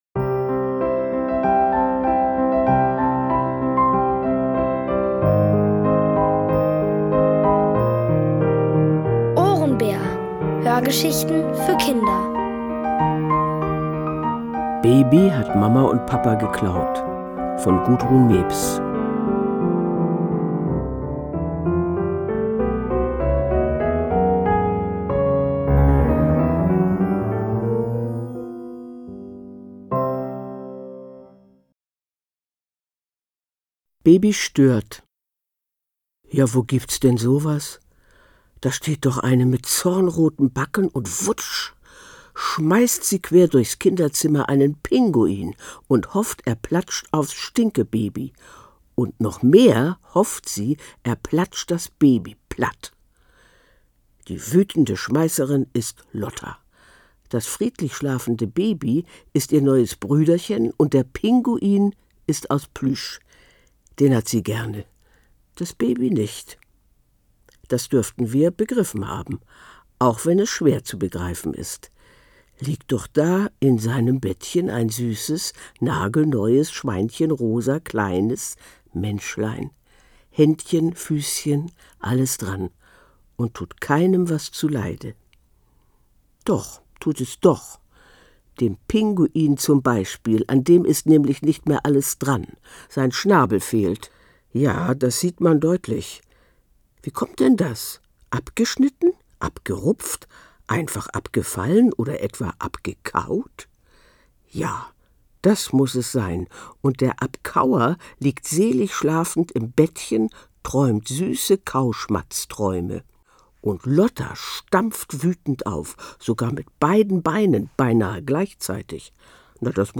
Von Autoren extra für die Reihe geschrieben und von bekannten Schauspielern gelesen.
Es liest: Hannelore Hoger.